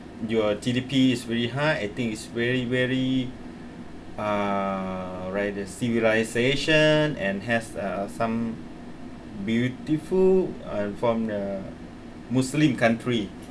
S1 = Brunei female S2 = Laos male
Intended Words: like the Heard as: rather
There is [r] instead of [l] at the beginning of like ; there is also no final [k] on this word.